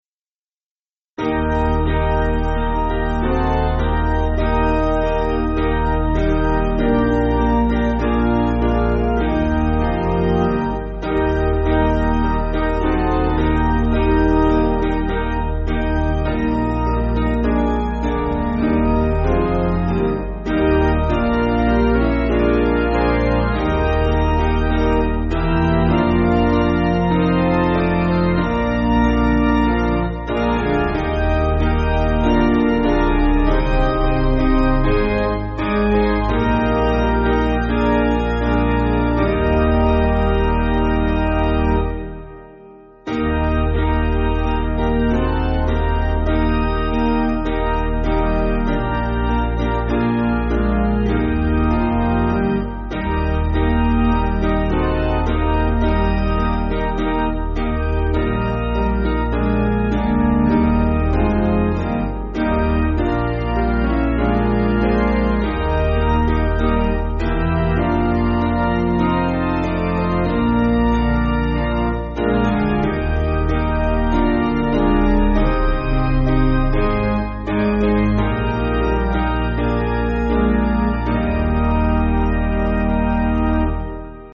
Basic Piano & Organ
(CM)   5/Eb